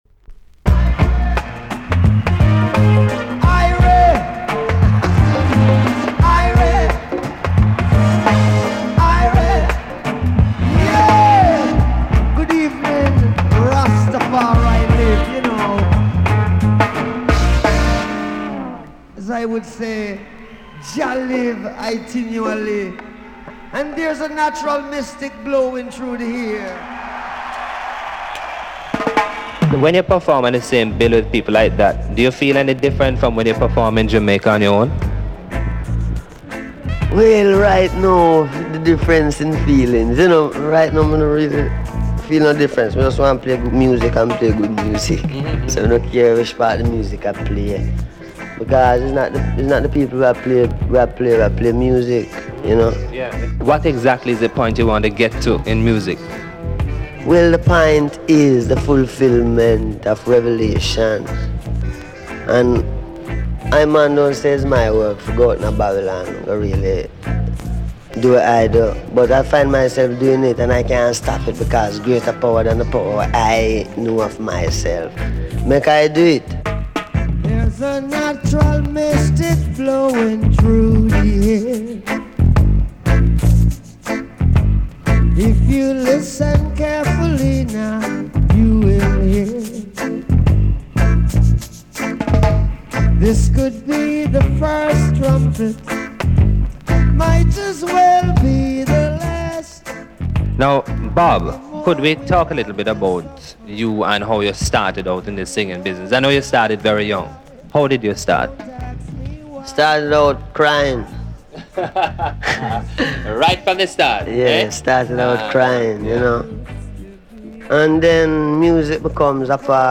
A.SIDE EX- 音はキレイです。
A.1 Interview